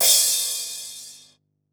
Crashes & Cymbals
CRASH114.WAV